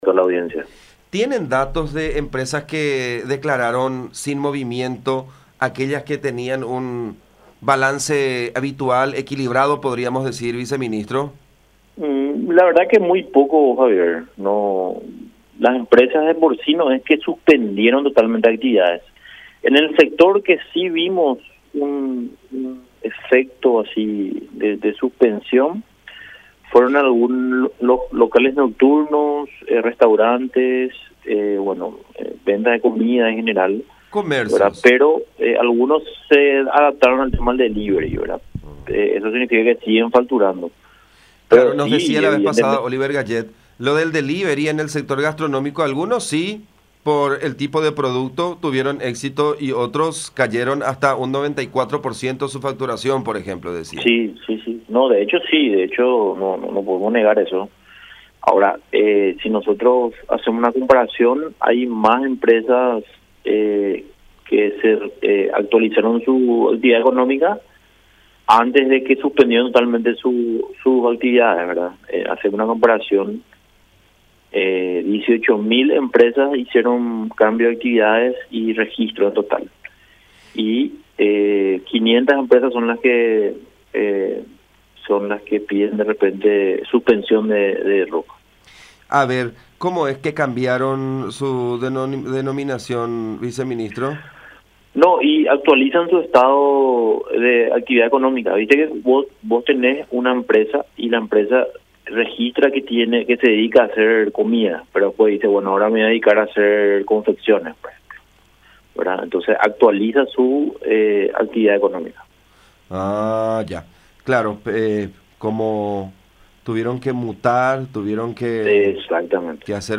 “El comercio declaró un monto mucho menor de lo consignado en la factura que presumiblemente fue clonada. Era la misma factura original, con el mismo número de timbrado, pero con los datos, tanto en el detalle como en los montos, que no corresponden con lo que declaró el vendedor”, explicó Orué en contacto con La Unión.